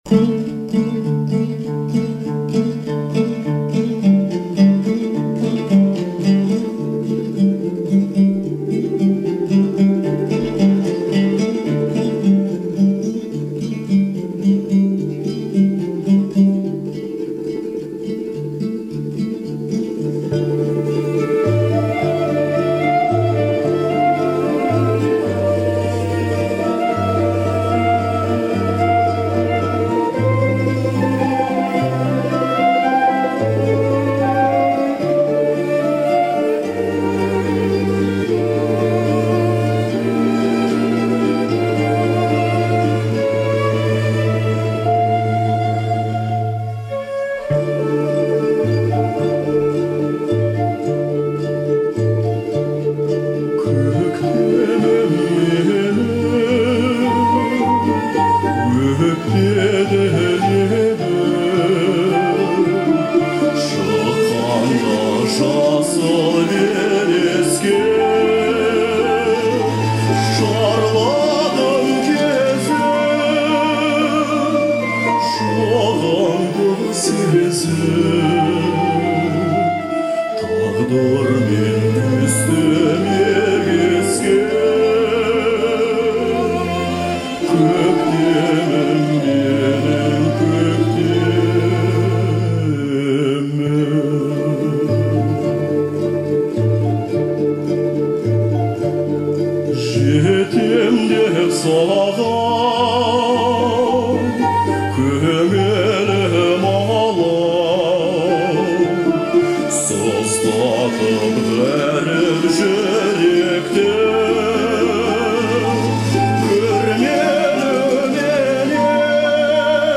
отличается мелодичностью и эмоциональной насыщенностью